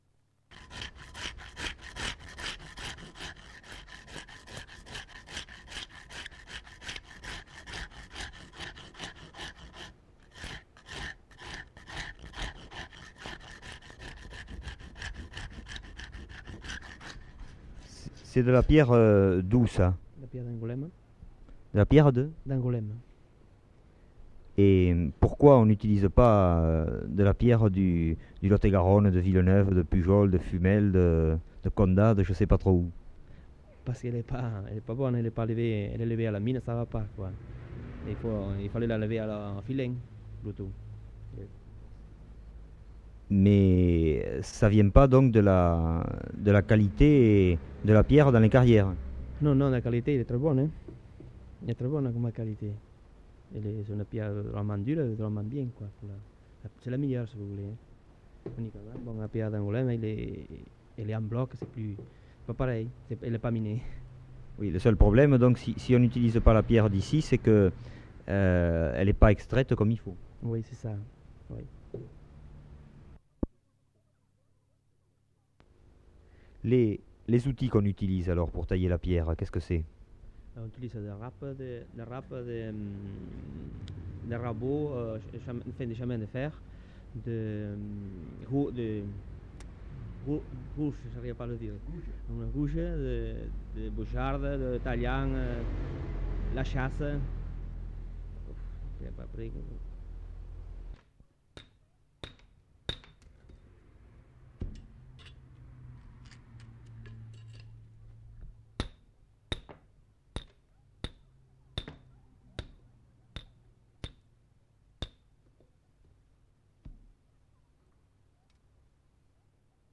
Nature du document : enquête
Type de son : mono
Qualité technique : moyen
Résumé : L'enregistrement est réalisé dans une carrière. L'enquêteur questionne successivement deux tailleurs de pierre sur le choix de la pierre et l'utilisation des outils.
Notes consultables : Enregistrement en plein air. Bruit du marteau sur la pierre.